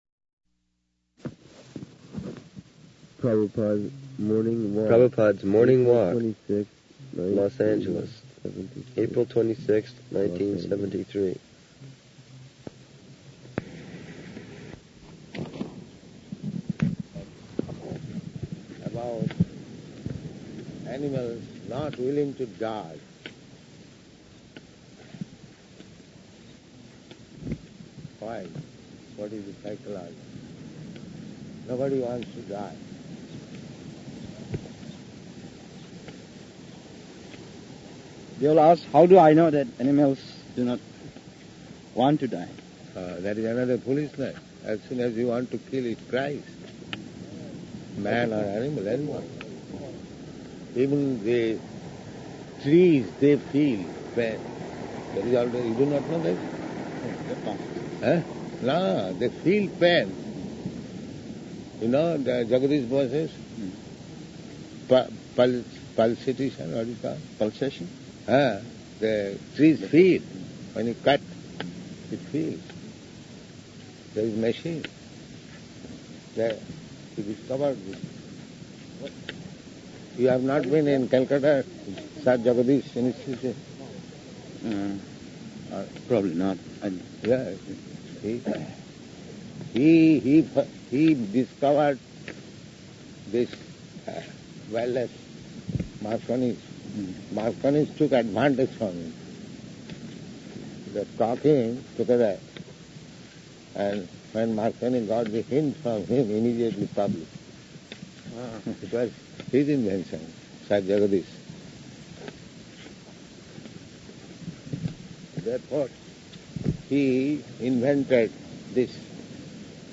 Srila Prabhupada Morning Walk, April 26, 1973